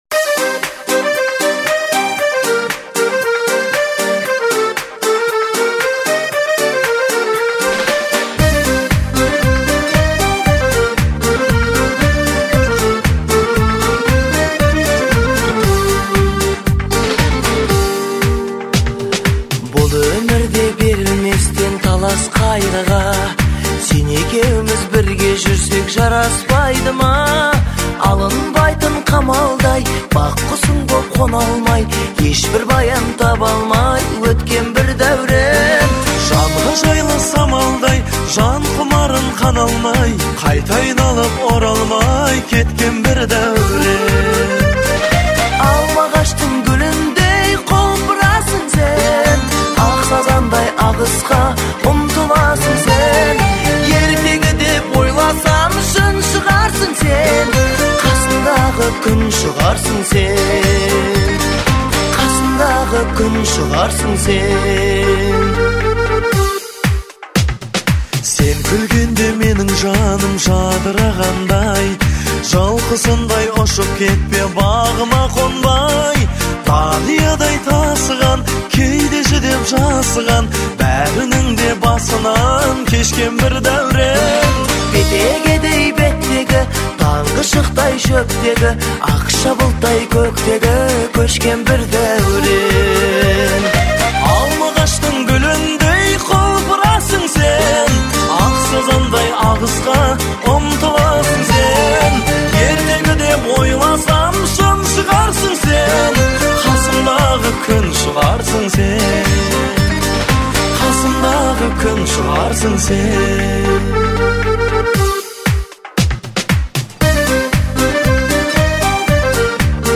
это трек в жанре поп с элементами этники
меланхоличное, но при этом вдохновляющее